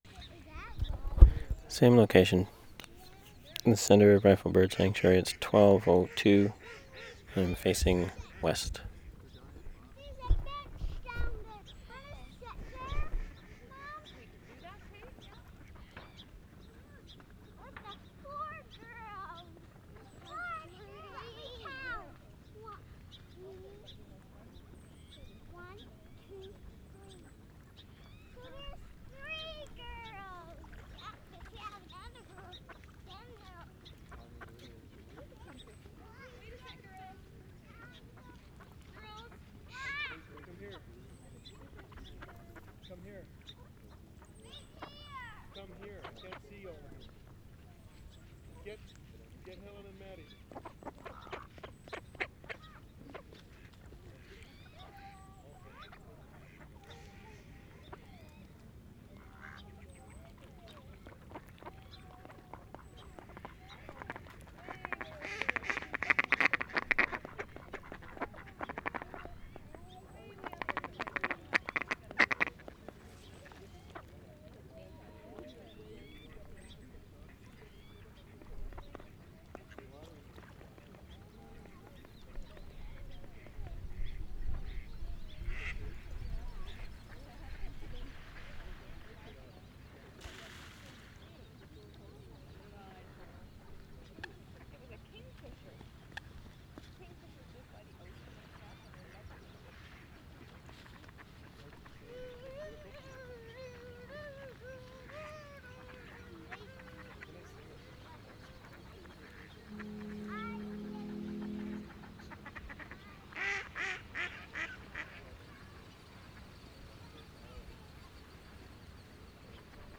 Reifel Bird Sanctuary, centre pond 6:34
5. ducks, children, small birds, people, closer ducks at 1:04, ducks land at 1:31 and 3:01, distant geese, jets at 3:00, 4:34 and 5:44 (airport), gun shot (?), footsteps, distant horn (boat?) at 1:49 and 2:18, child coughs at 3:51